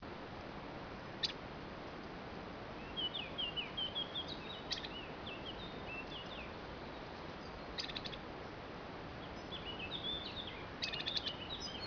c'è un uccellino nascosto tra cespugli ed arbusti, non si fa quasi vedere ma si fa sentire con questi 'tocchi' anche a poca distanza.
Siamo in Toscana, agosto 2012 a 300 m.
Scricciolo ?